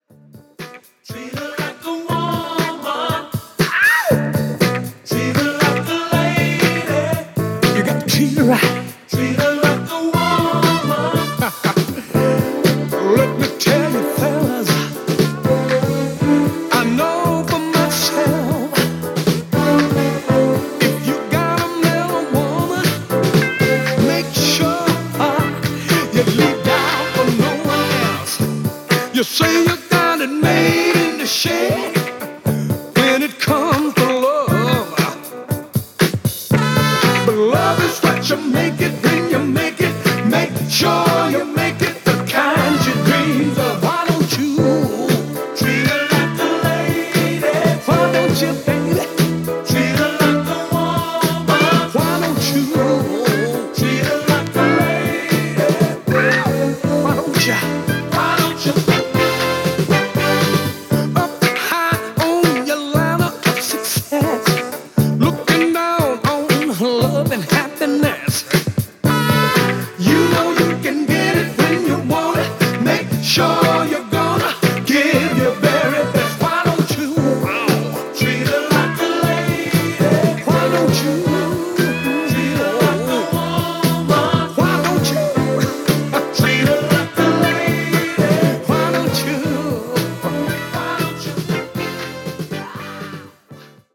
Soul Funk Disco